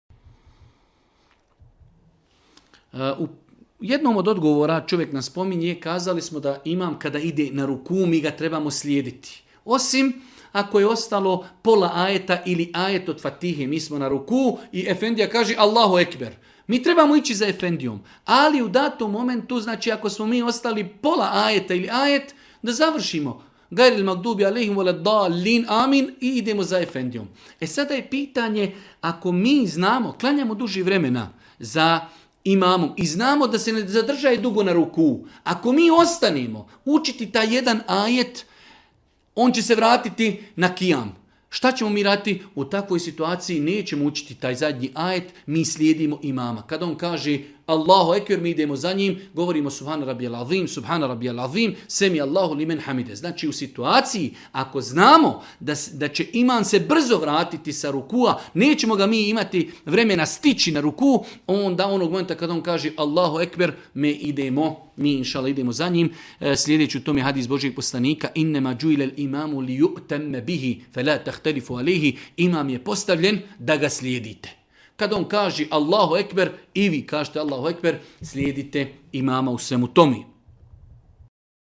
u video predavanju